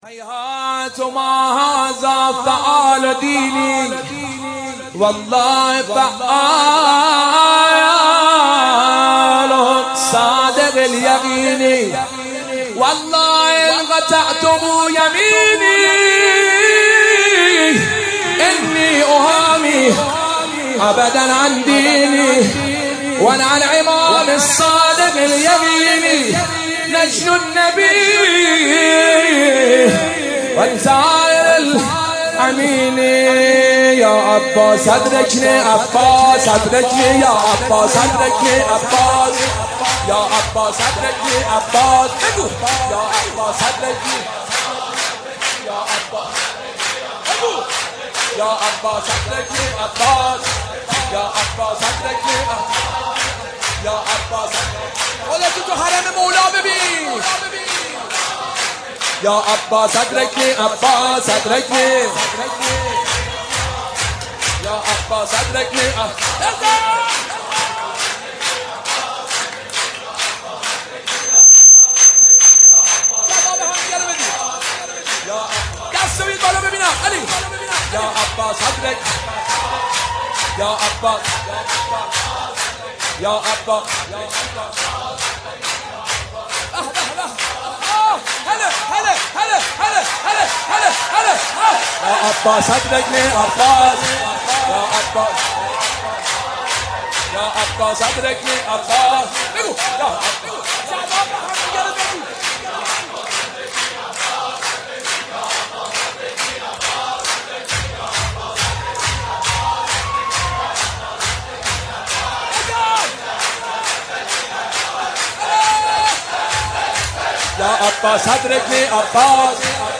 شور ولادت قمربنی هاشم حضرت عباس(ع
مداح